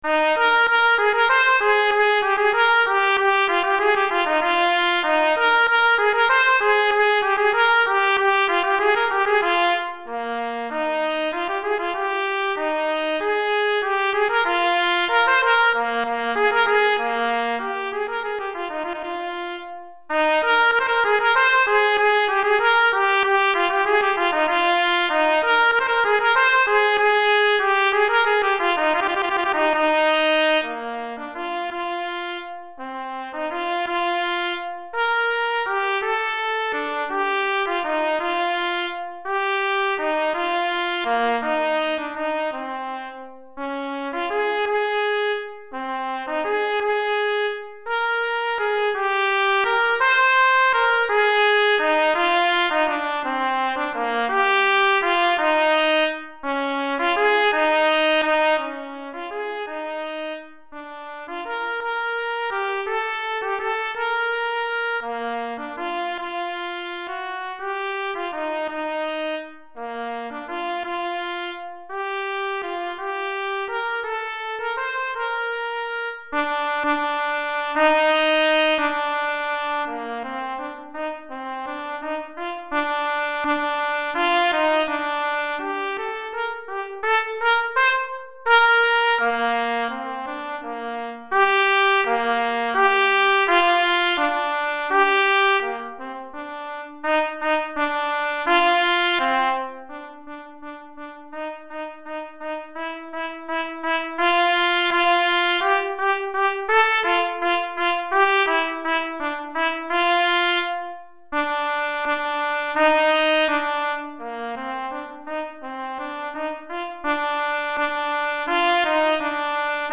Trompette Solo